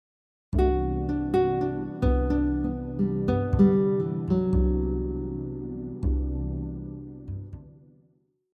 This last example also uses a G Major 7 arpeggio with a descending note and rhythm pattern.
Major-7-arpeggio-example-5.mp3